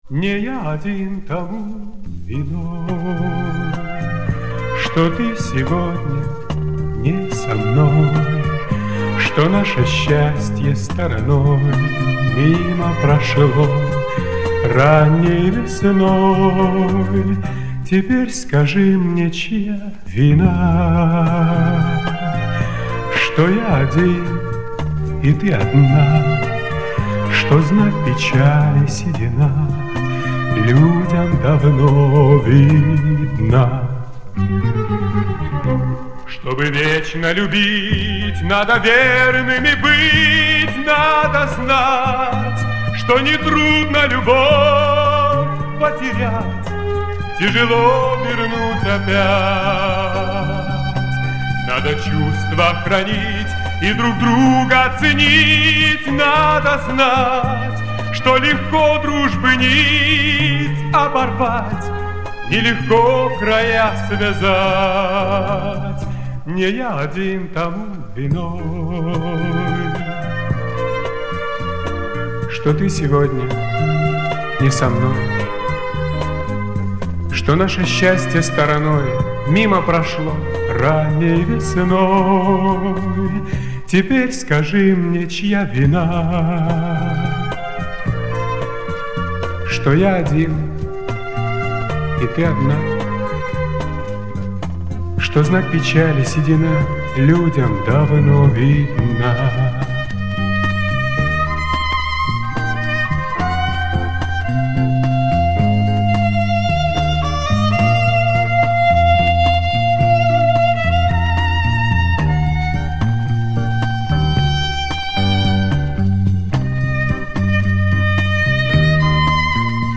Похоже на украинское или молдавское.